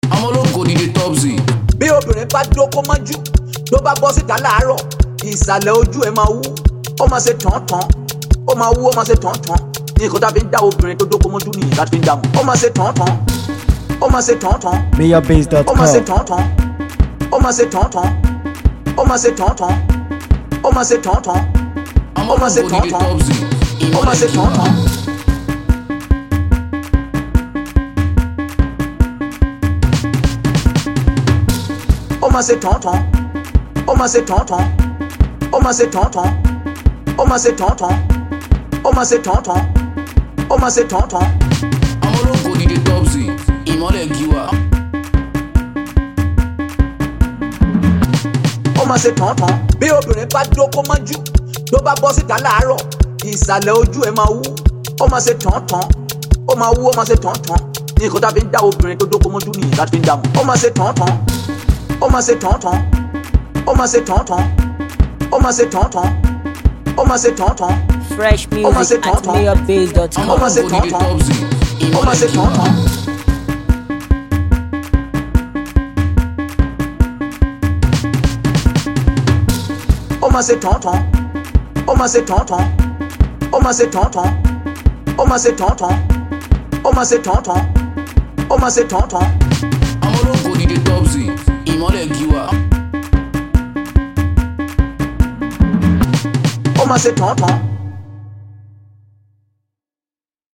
street Beat